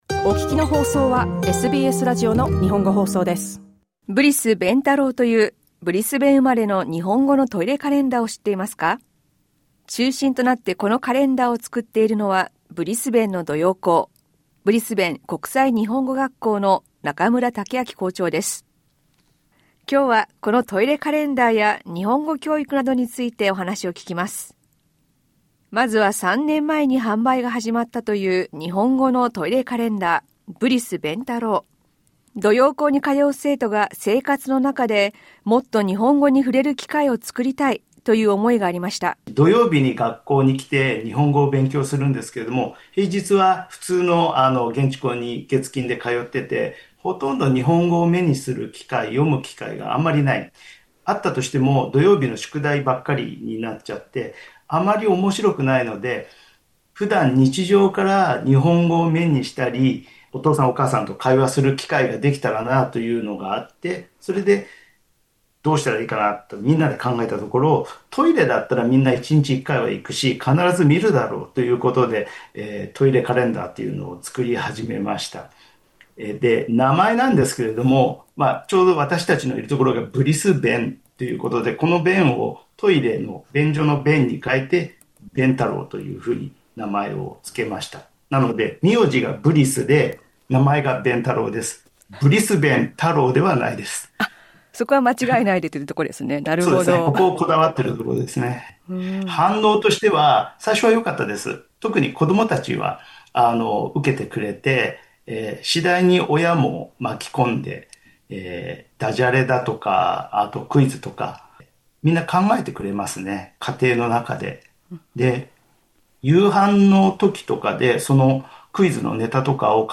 インタビューでは、ブリス便太郎とブリスベン国際日本語学校について聞きました。